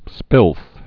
(spĭlth)